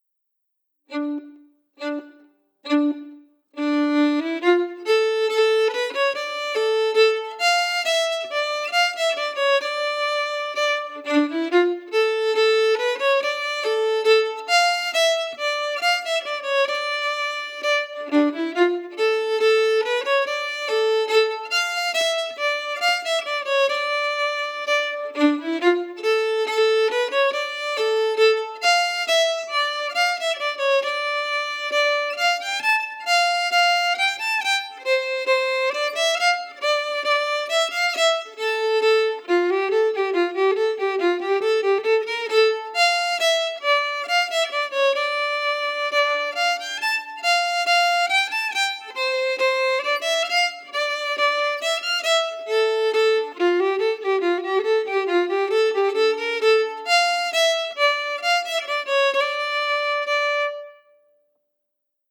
Key: Dm*
Slow for learning
R:Reel (8x40) ABABB
Region: Scotland